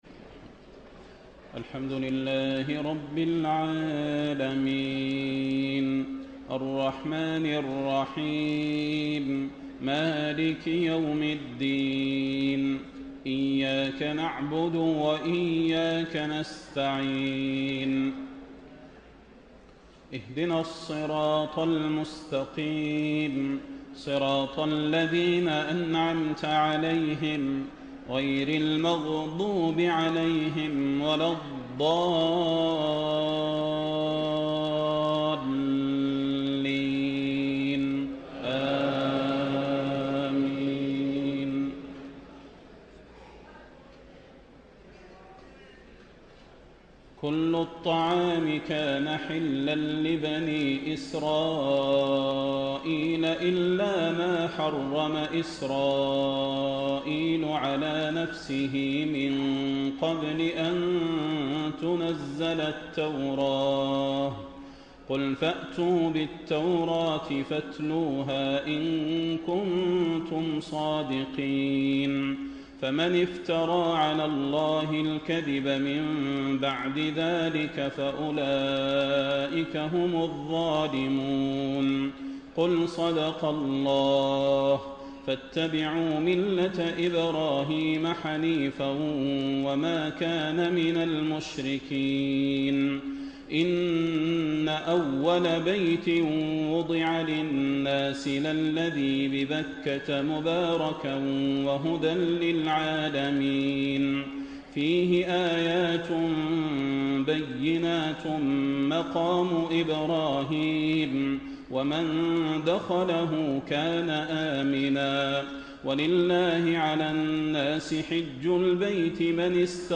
تهجد ليلة 24 رمضان 1434هـ من سورة آل عمران (93-185) Tahajjud 24 st night Ramadan 1434H from Surah Aal-i-Imraan > تراويح الحرم النبوي عام 1434 🕌 > التراويح - تلاوات الحرمين